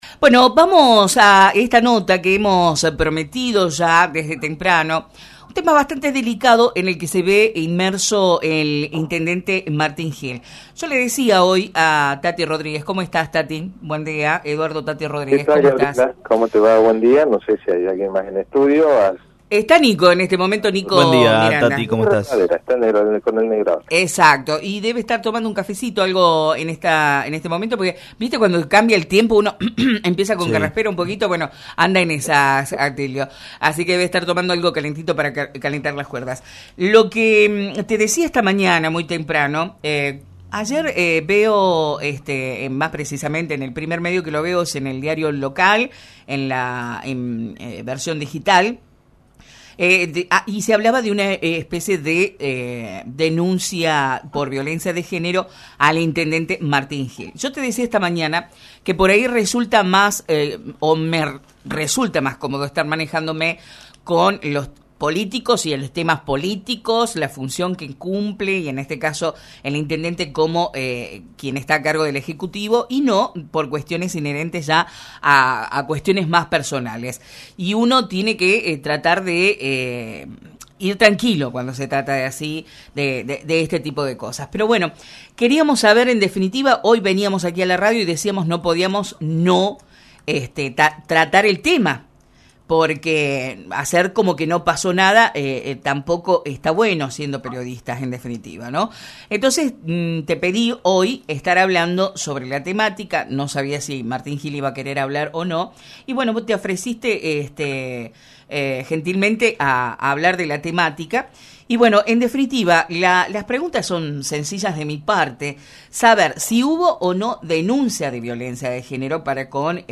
El actual Secretario de Gobierno, Seguridad Ciudadana y Asuntos Legales se refirió hoy en «La Mañana Informal» a la situación judicial en la que se vio inmerso el actual intendente Martín Gill por una denuncia radicada en los Tribunales locales.